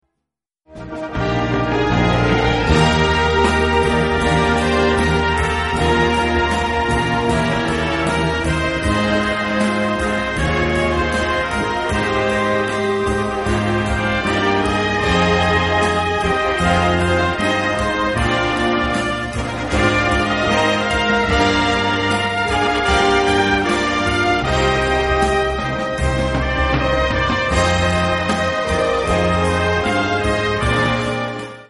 Gattung: Solo für diverse Instumente und Blasorchester
Besetzung: Blasorchester
Mit Solo-Gesang oder Solo-Instrument in B, C, Es oder F.